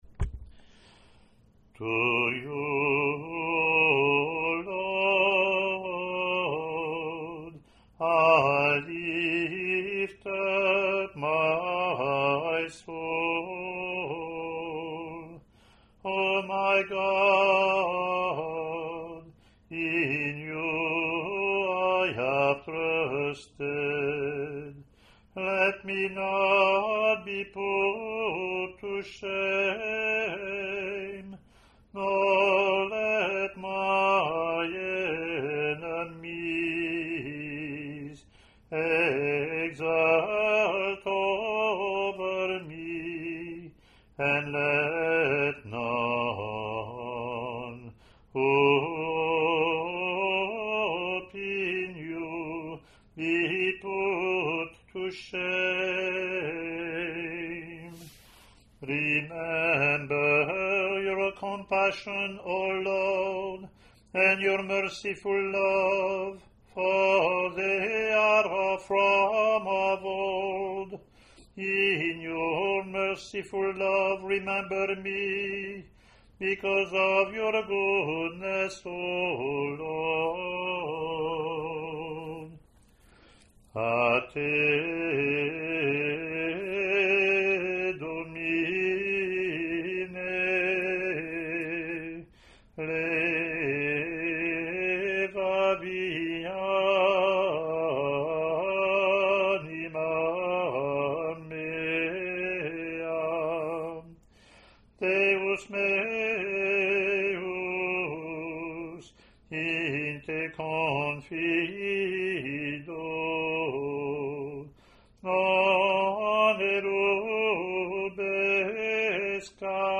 English antiphon – English verse– Latin antiphon)